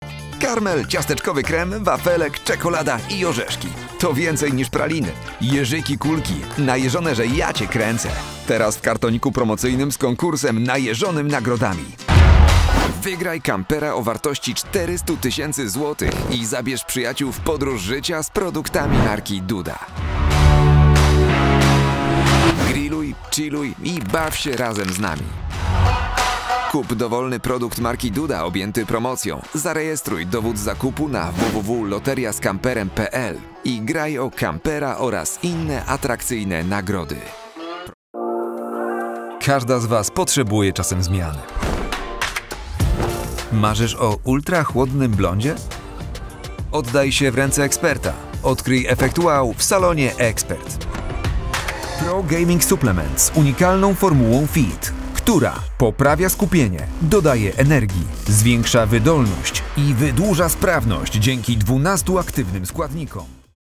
Male
Corporate Videos
I am a Polish native speaker and I have been recording in my own studio for several years now.
Neumann TLM 103, Focusrite Scarlett, Reaper
BaritoneBassLow
ProfessionalFreshYoungInformativeNeutral